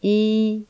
雅文檢測音
i_-15db.mp3